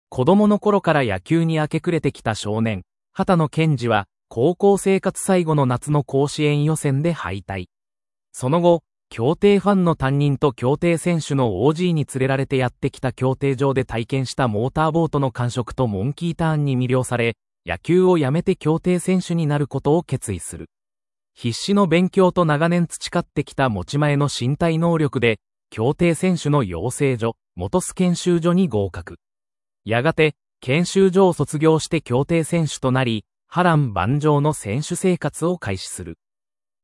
ナレーション 音読さん